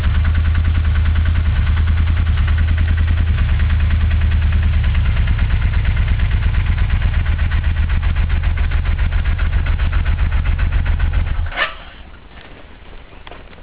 Auspuff-Sounds
Original Auspuff